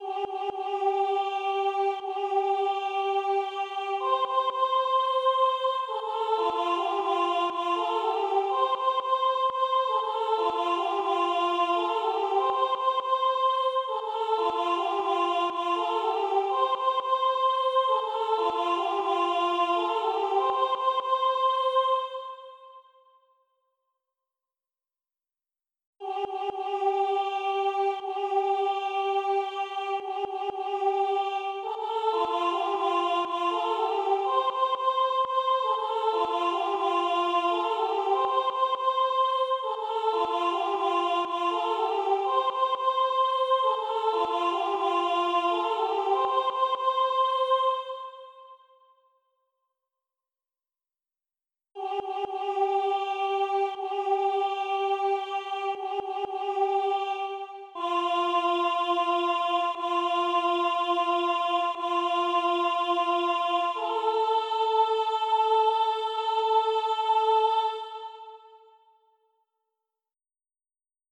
Alto - HAH voix Ap